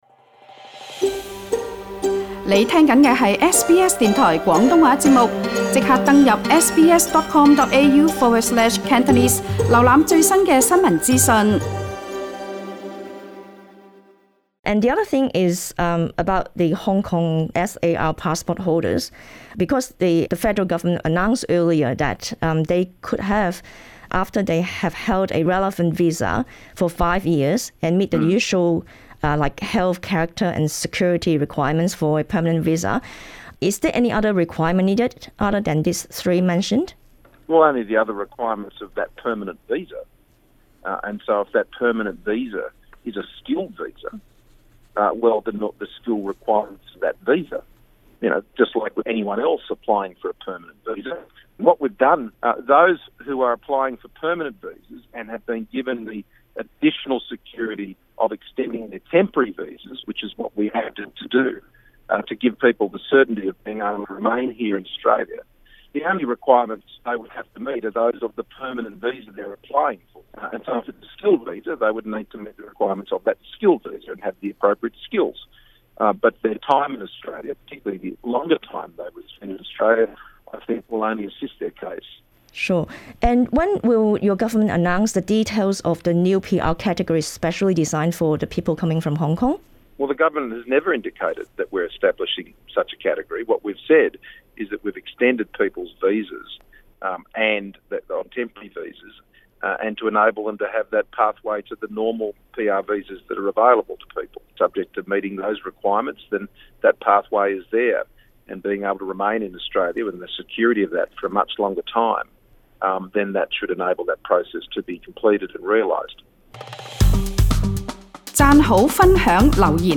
【总理独家专访】港人5年临签到期后 申请永居须具「适当技能」
要点： 莫理逊表示，获延长临时签证的港人，在具备「适当技术」下可申请一款技术永居签证 移民部发言人解释，不会为港人专设一项新签证，而是让他们优待条件申请「现有」的永居签证 有港人表示，澳洲政府迟迟未公布永居签证政策，令她对前路感到不明确 莫理逊趁昨日（2月11日）农历大年三十，接受本台广东话组专访，谈到港人双重国籍等议题。